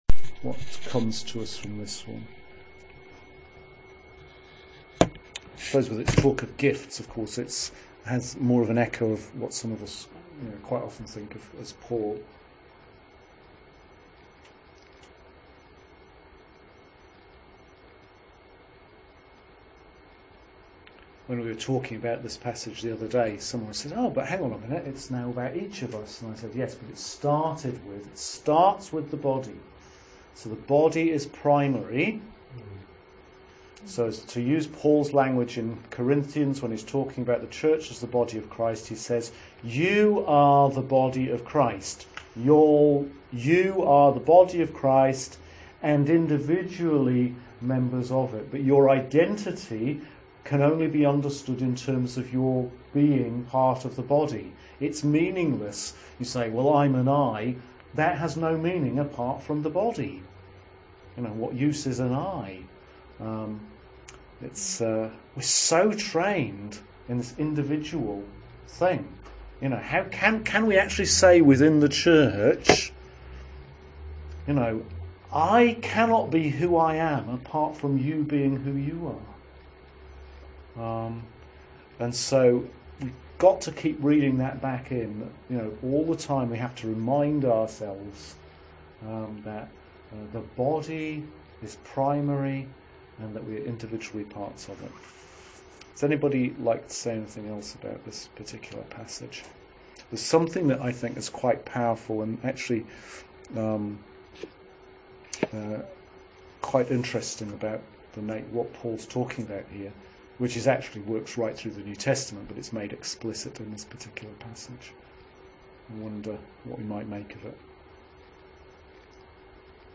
Sermon for Lent 4 – Year A – March 30th 2014